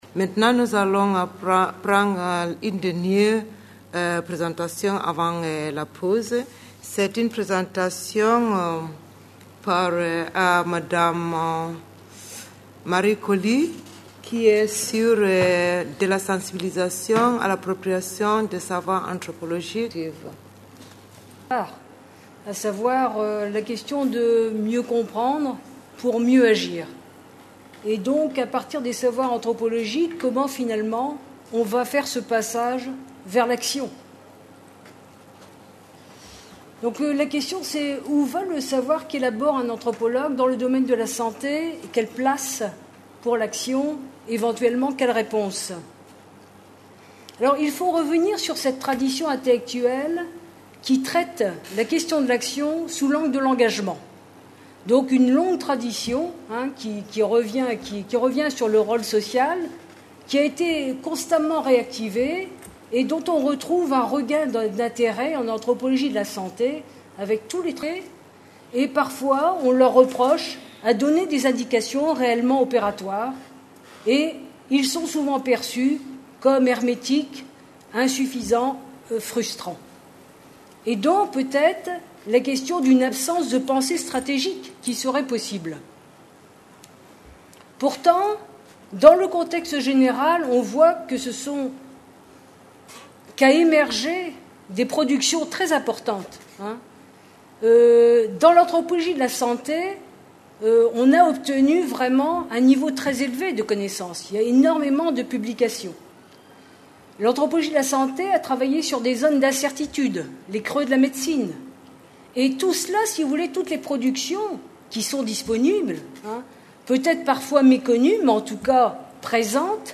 Titre : de la sensibilisation à l’appropriation des savoirs anthropologiques : quels processus, quelles perspectives ? Conférence enregistrée dans le cadre du Colloque International Interdisciplinaire : Droit et Santé en Afrique.